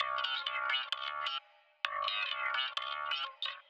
Electric Guitar 04.wav